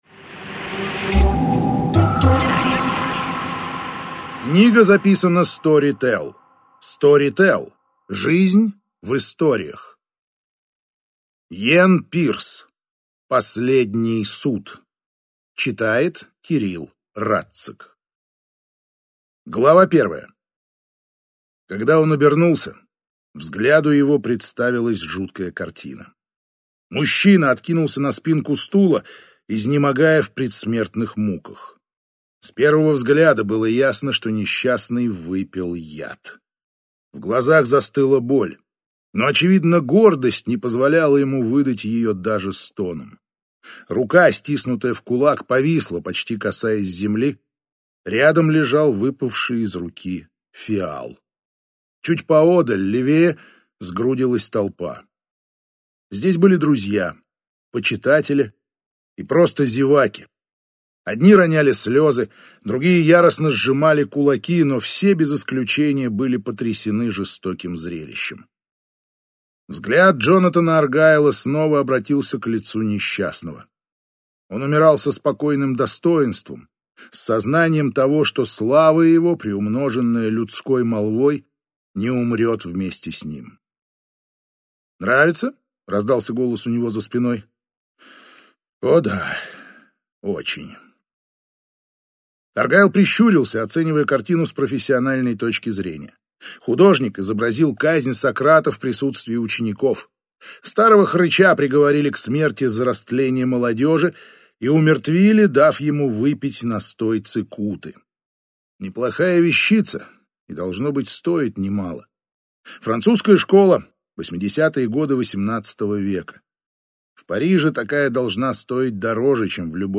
Аудиокнига Последний суд | Библиотека аудиокниг